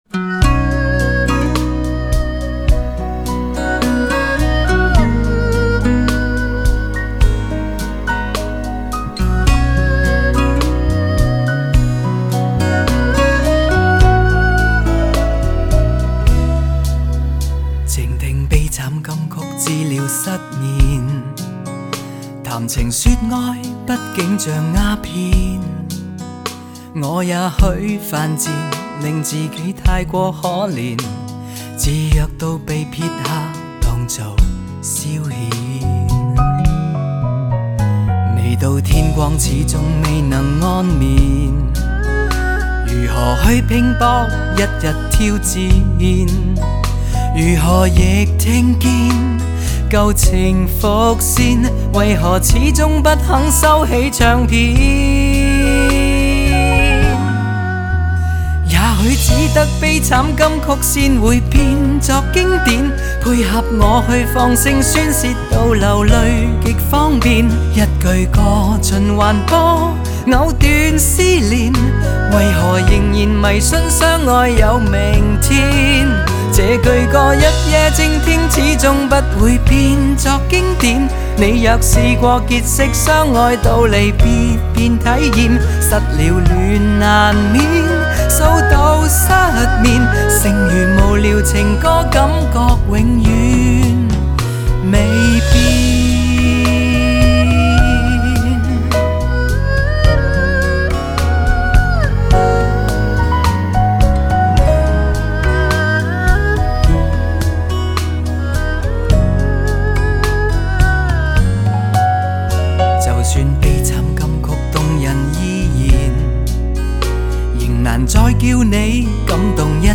邰式经典情歌